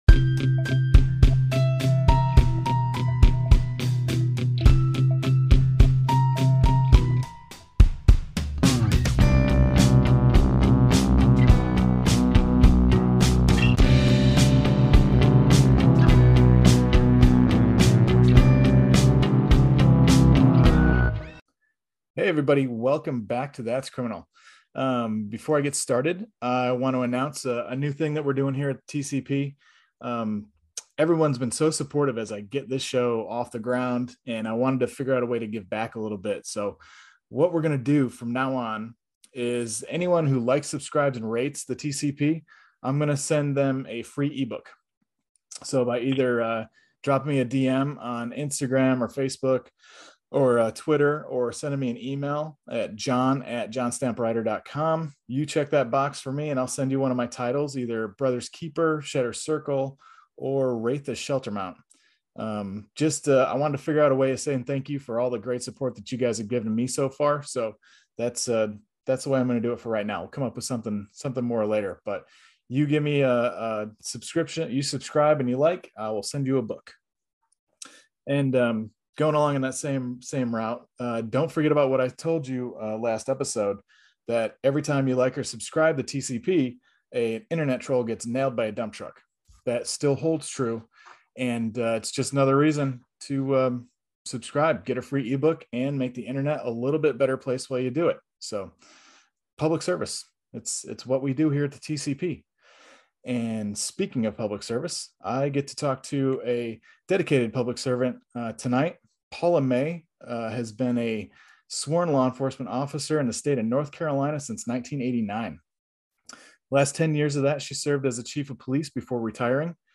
This was a great interview. We discussed everything from a frozen crime scene in the middle of winter, to going head to head with a murderer in the interrogation room.